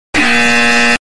lie-buzzer-made-with-Voicemod.mp3